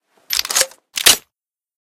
reload_loop.ogg